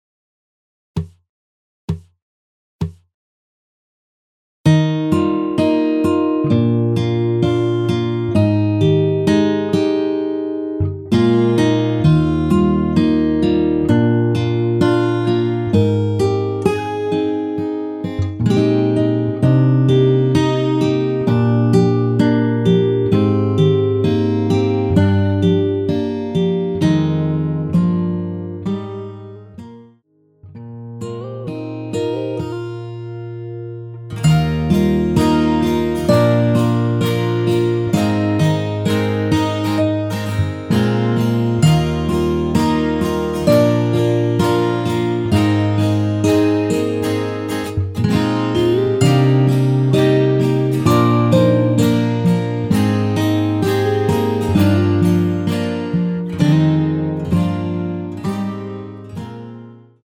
전주없이 시작 하는 곡이라 카운트 넣어 놓았습니다.(미리듣기 참조)
Eb
앞부분30초, 뒷부분30초씩 편집해서 올려 드리고 있습니다.
중간에 음이 끈어지고 다시 나오는 이유는